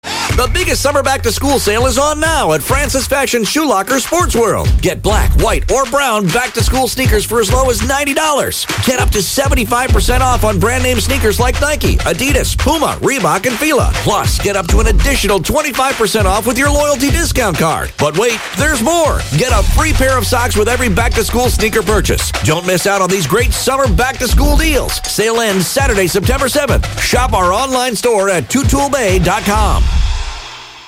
Top 5 Radio Ads
Francis Fashions Shoe Locker / Sportworld’s spot at the top features a voice over ad of 30 seconds which is tailored to the target audience.